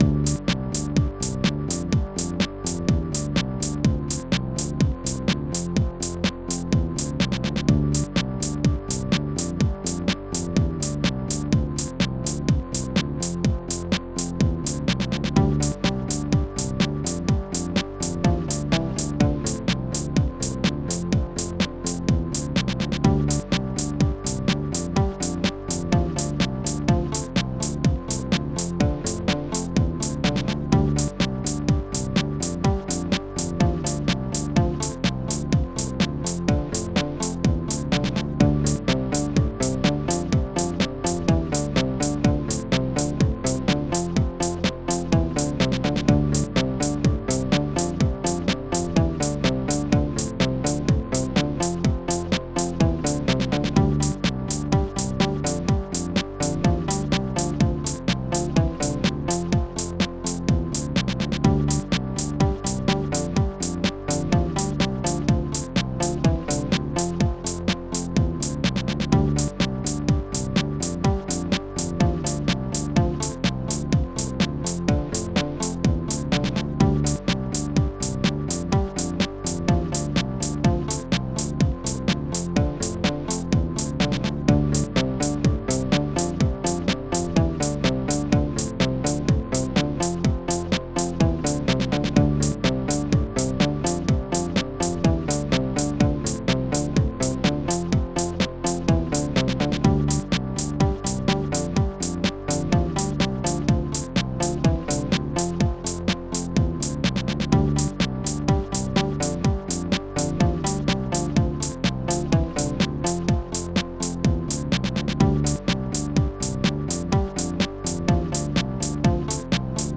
Tracker AMOS Music Bank
Instruments st-88:gbass st-03:church st-88:snare13 st-03:bastrumma st-03:bassdrum6 st-03:guitar7